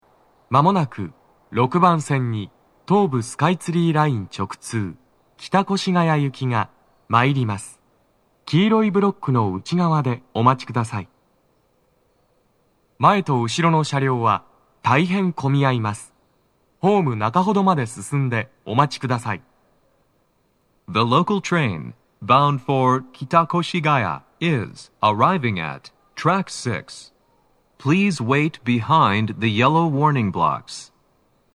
鳴動は、やや遅めです。
男声
接近放送4